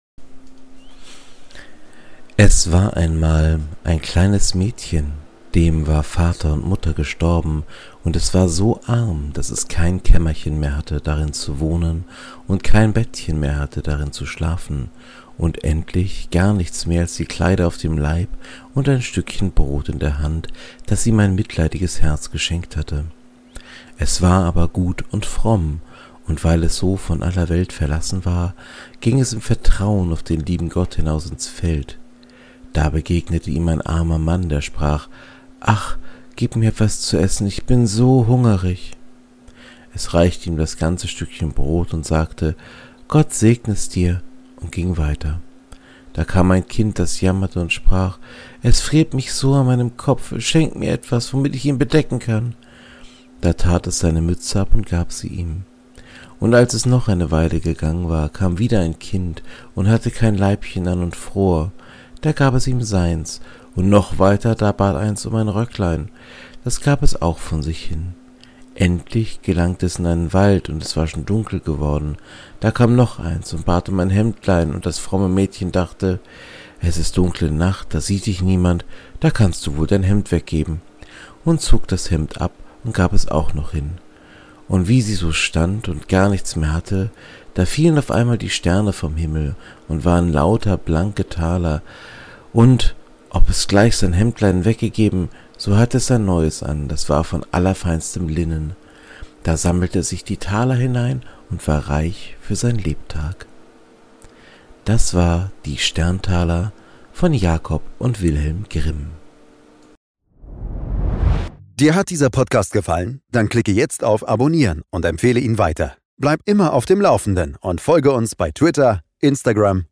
In diesem kleinen Podcast Projekt lese ich Märchen vor. Dabei nutze ich die Texte aus dem Projekt Gutenberg.